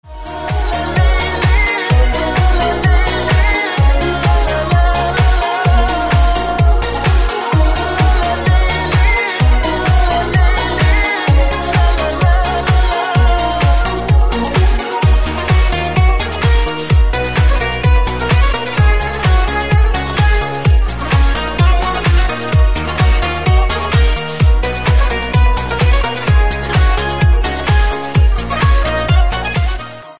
• Качество: 128, Stereo
громкие
remix
восточные мотивы
Dance Pop